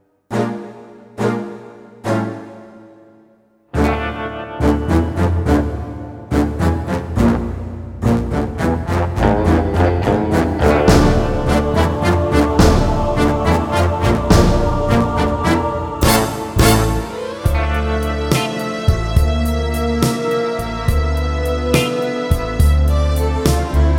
Two Semitones Down Jazz / Swing 4:13 Buy £1.50